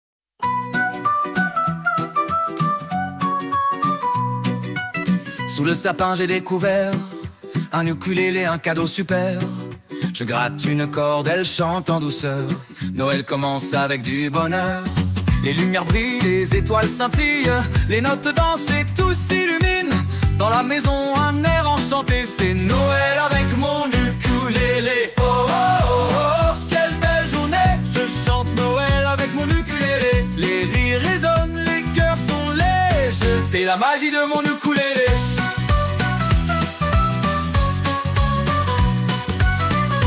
Catégorie Noël